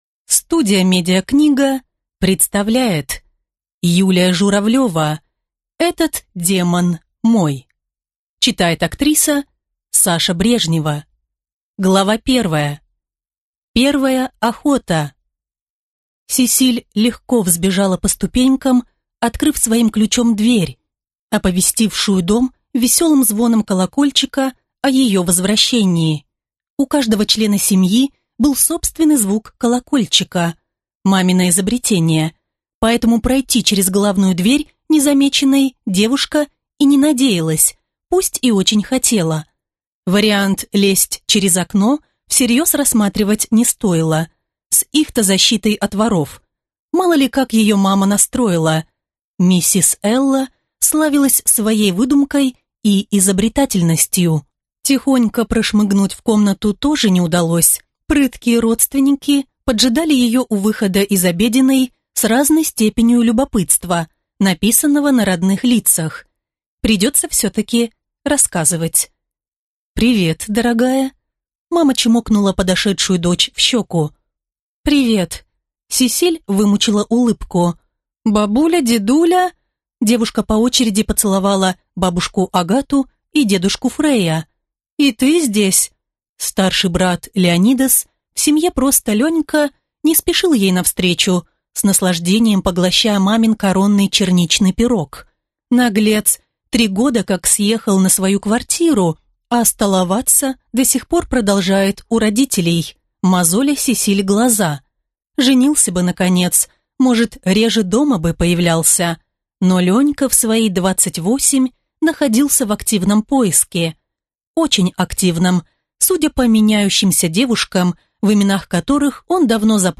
Аудиокнига Этот демон мой! | Библиотека аудиокниг